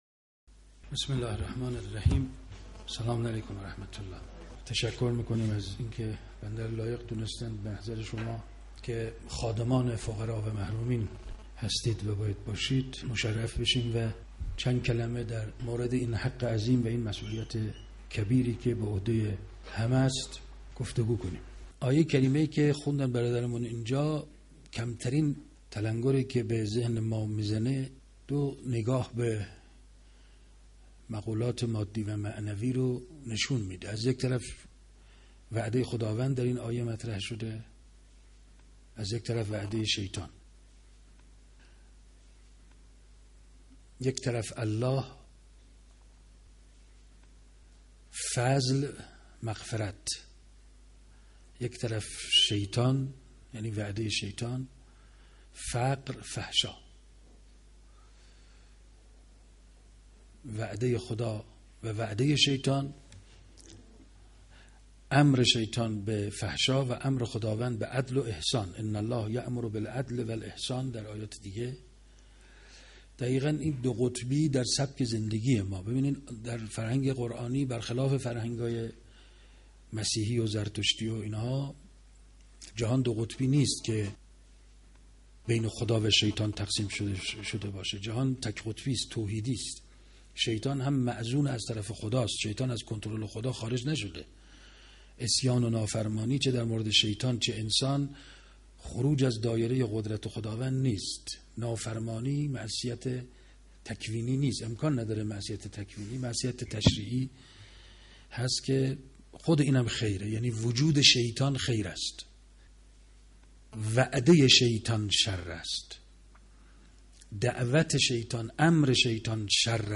جمعی از مدیران کمیته امداد امام خمینی (ره) – تهران 94